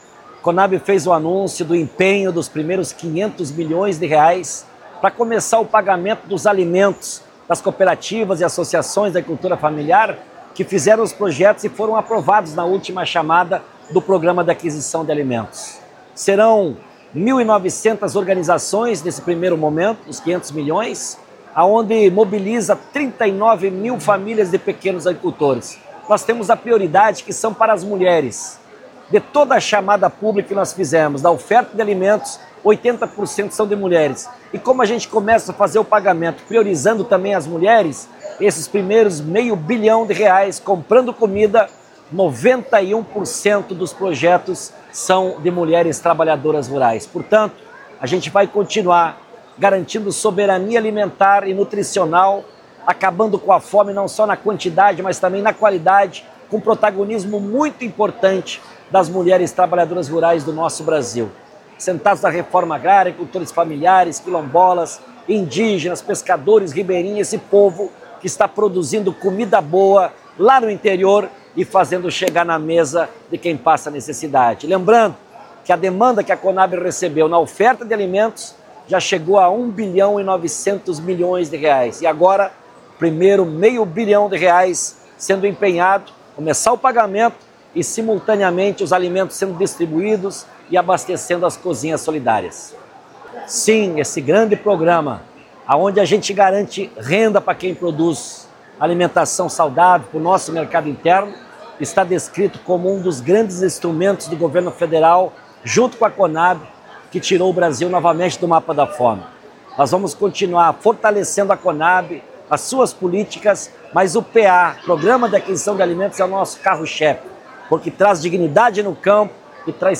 PAA-Edegar-Pretto-presidente-da-Conab.mp3